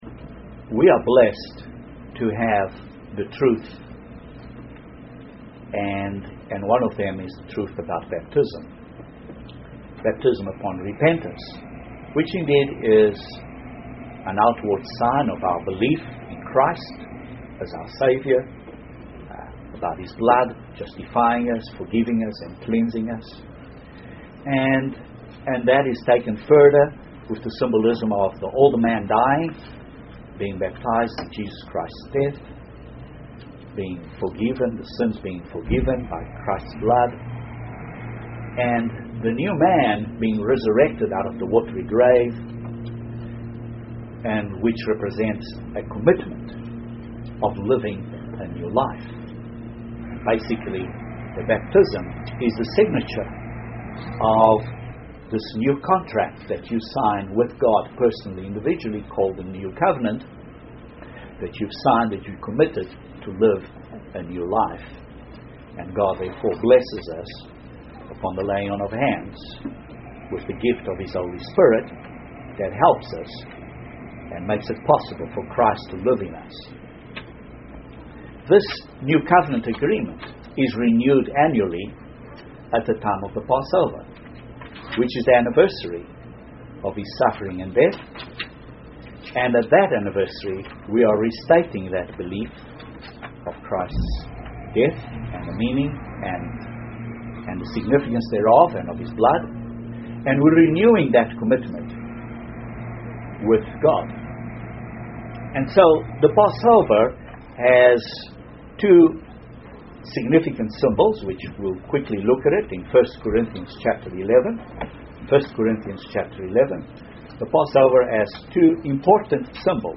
In preparation for the Passover this sermon looks at a few spiritual analogies from the symbolism of the Passover Bread.